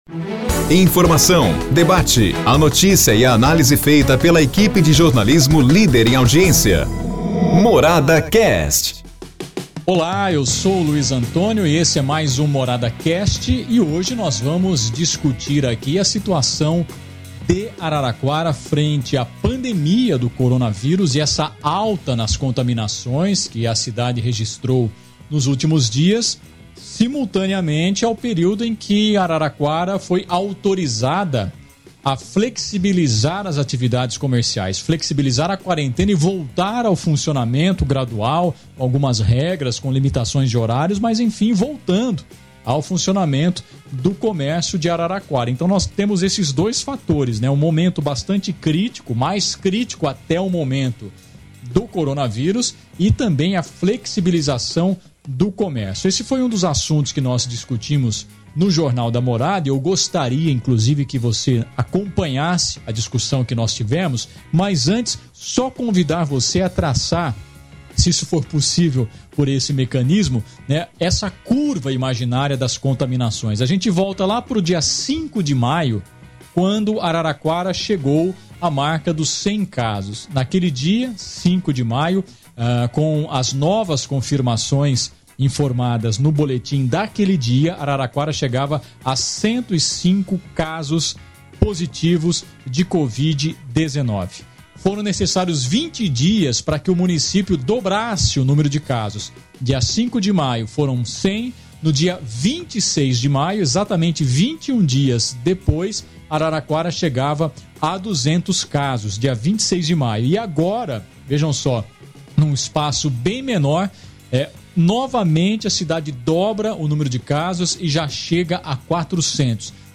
Neste episódio do MoradaCast, nosso time jornalístico faz um debate de suma importância para o momento, acerca das consequências da flexibilização do isolamento proposta pelo governo do Estado de São Paulo.
O trecho, recuperado do Jornal da Morada, contém também uma reflexão acerca da problemática do transporte público nesse momento de pandemia.